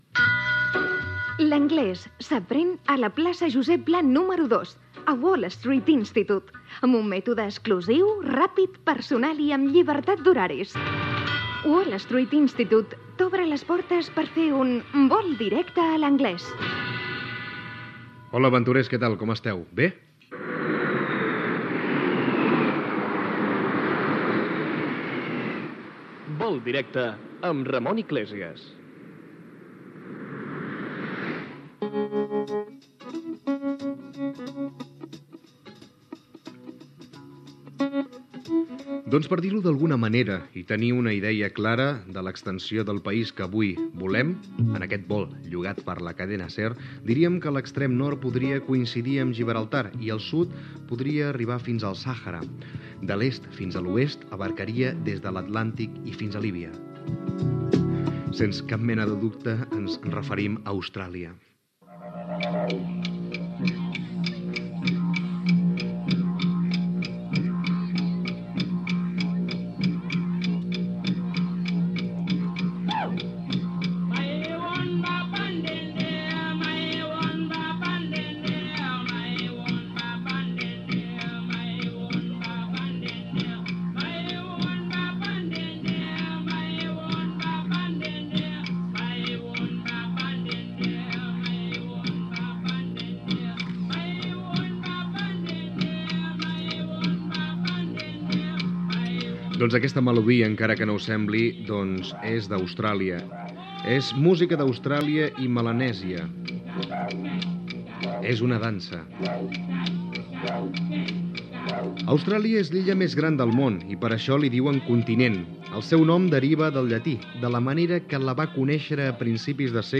Publicitat, indicatiu del programa, descripció i dades d'Austràlia
Divulgació